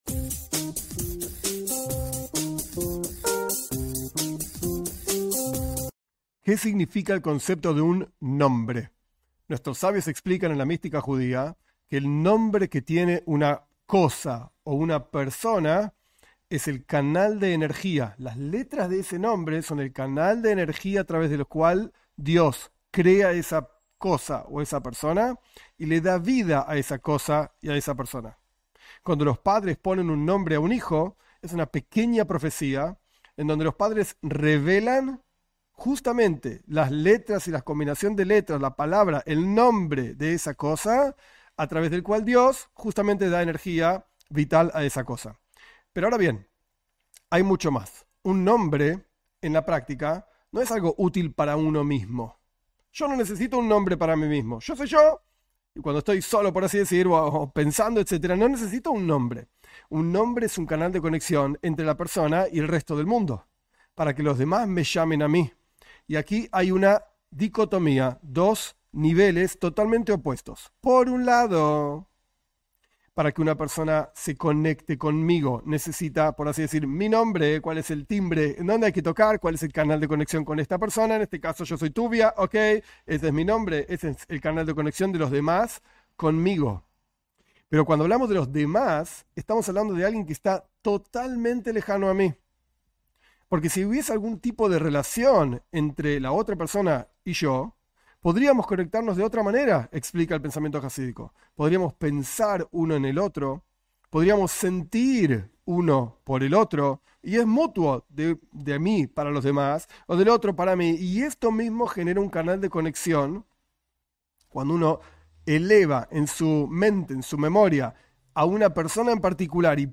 En esta clase se explica, desde una perspectiva jasídica, el significado de un "nombre". Se trata de un cana de energía entre Di-s y las personas y entre las personas mismas.